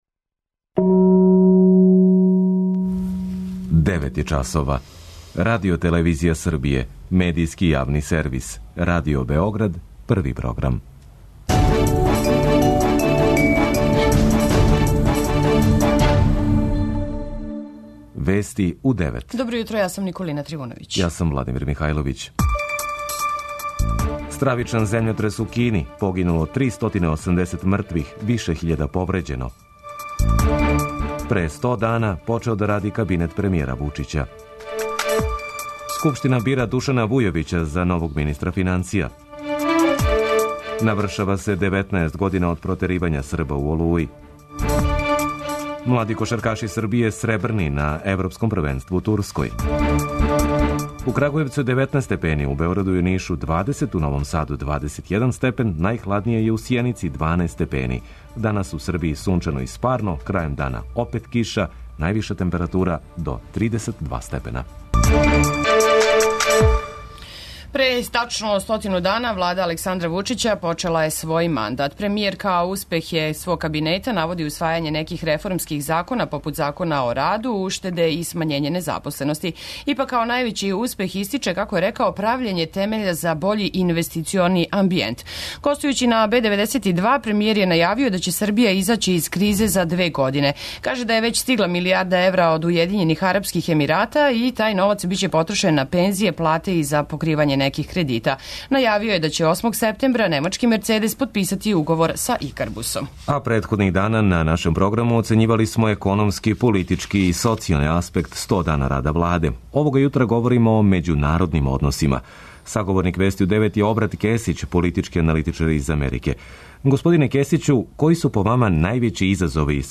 преузми : 10.26 MB Вести у 9 Autor: разни аутори Преглед најважнијиx информација из земље из света.